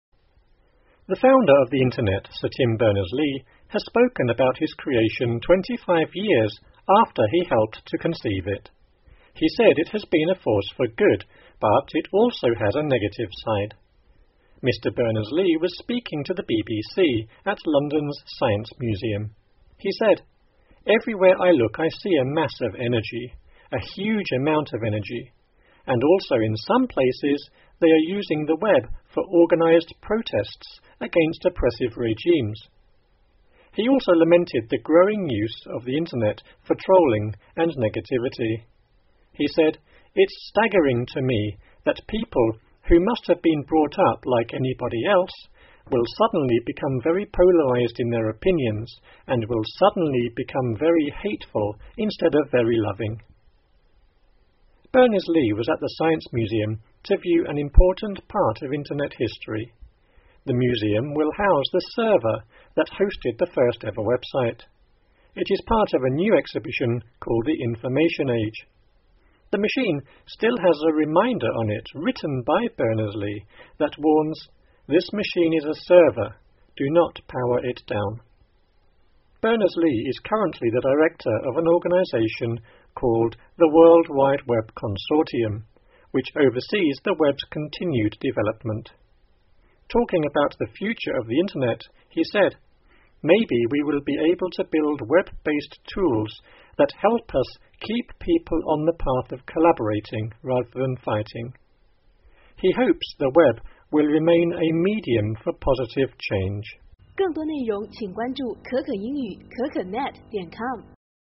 新奇事件簿 互联网创始人Sir Tim Berners-Lee讲话 听力文件下载—在线英语听力室